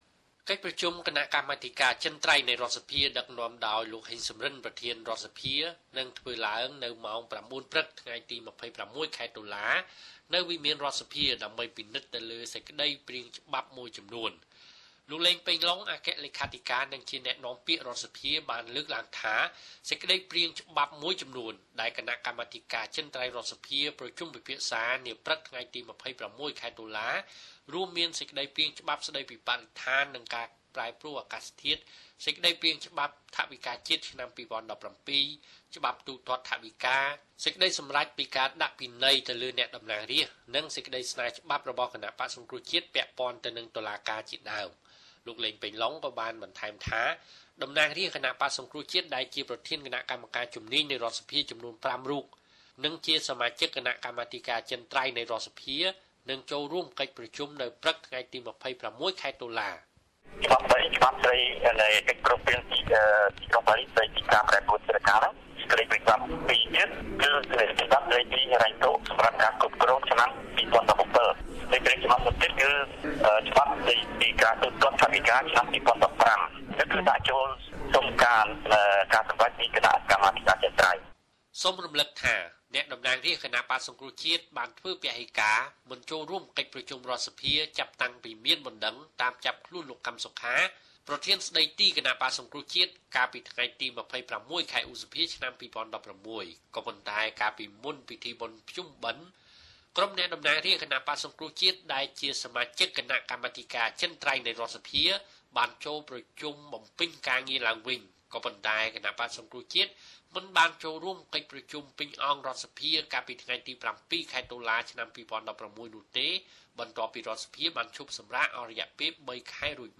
(សំឡេងលោក ឡេង ប៉េងឡុង)